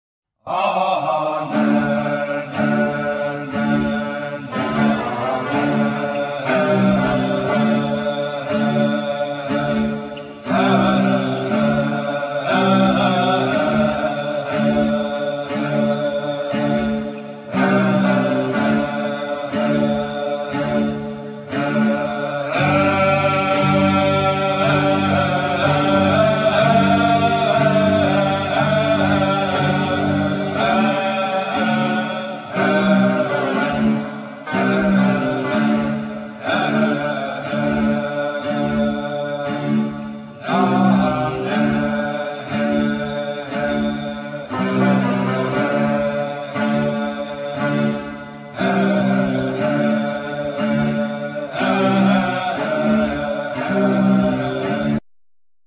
Vocal,Bagpipes,Flute
Guitar,Mandolin,Flute,Back vocals
Drums,Percussions,Keyboards,Back vocal